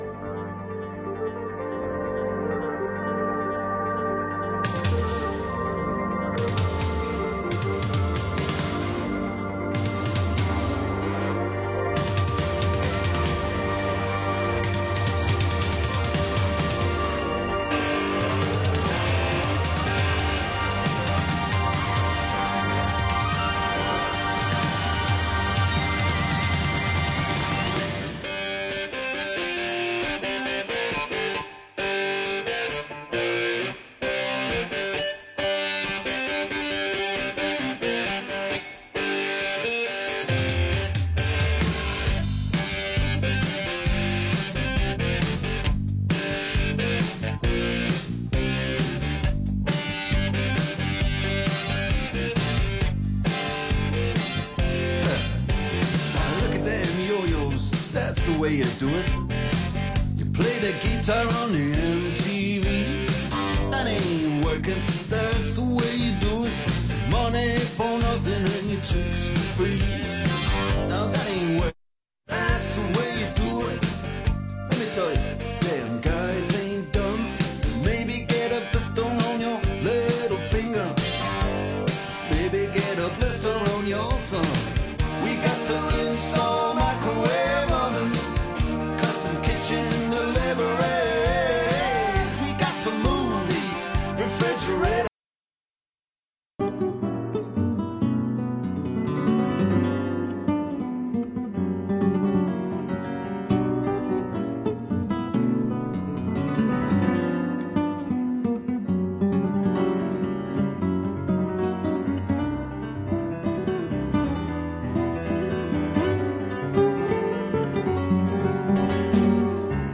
Фрагмент тестовой записи DRM из Псарьков